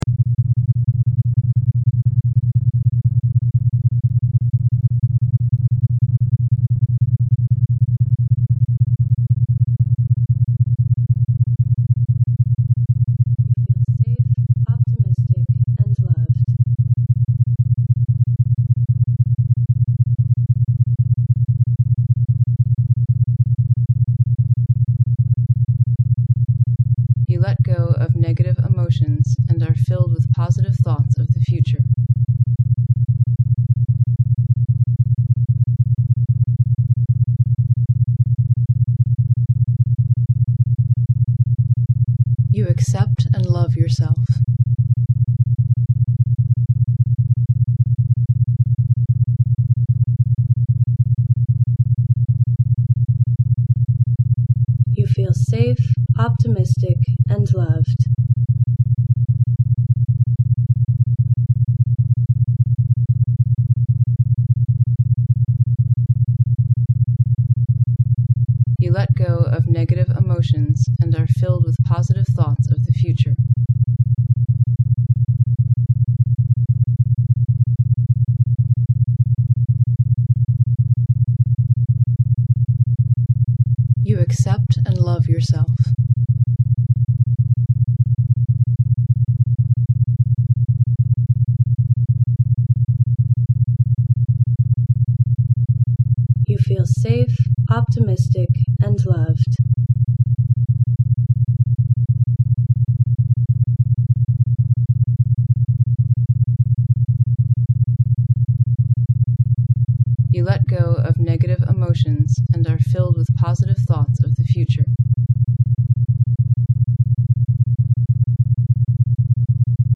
SELF HEALING MP3 BINAURAL BEATS DEPRESSION ANXIETY HELP
DEPRESSION REDUCTION WITH AFFIRMATIONS -
This session works as above but has added affirmations relating to security, self appreciation and the ridding of negative emotion.
Use earphones - the correct effects can only be gained in this way
PREVIEW-Depression-Reduction-Session-affirmations.mp3